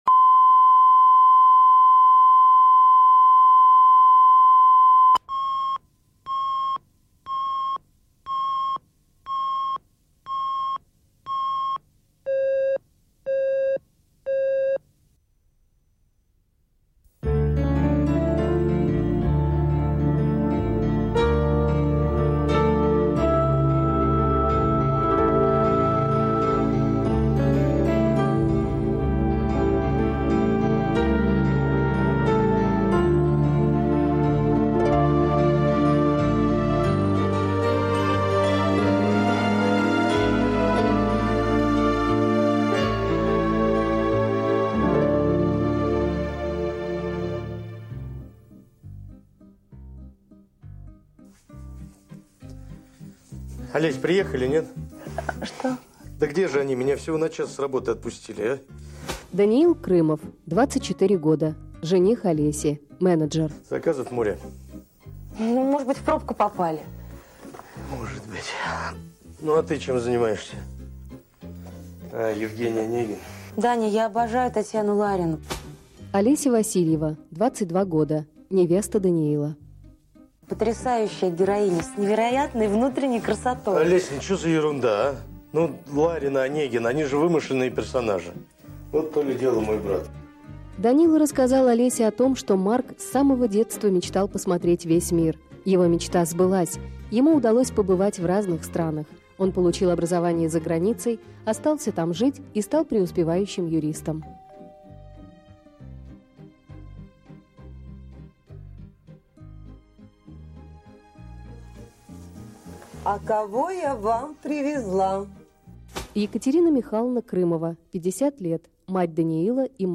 Аудиокнига Дарлинг